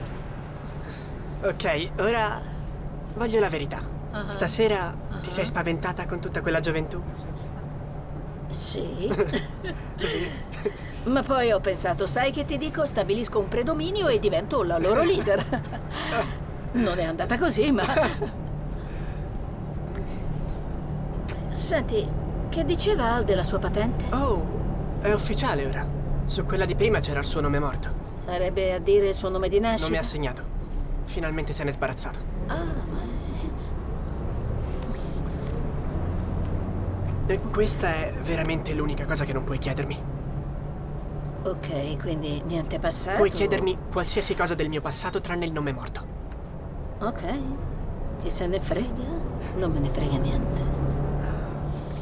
nel telefilm "Work in Progress", in cui doppia Theo Germaine.